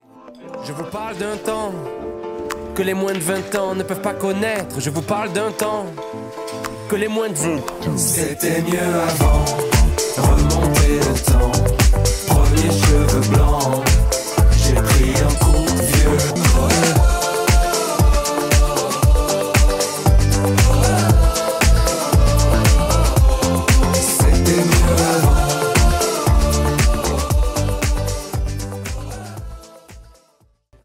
chanson douce et nostalgique